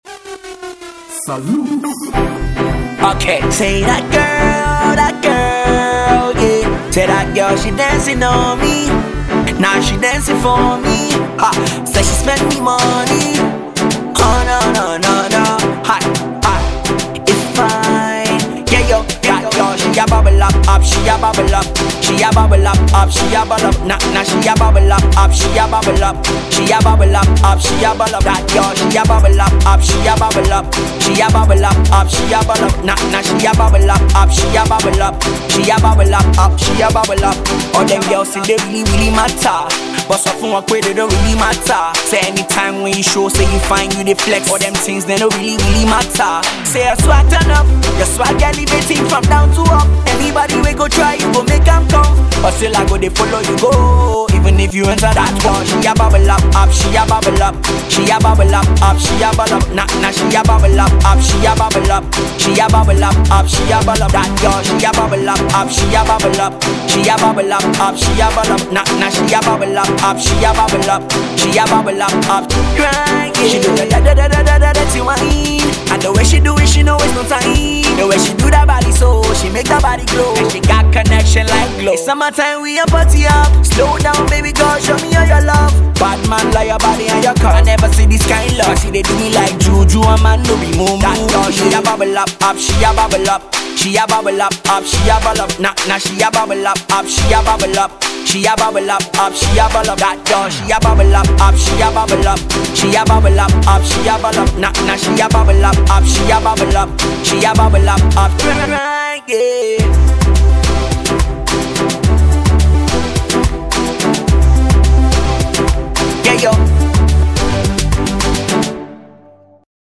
Naija Crooner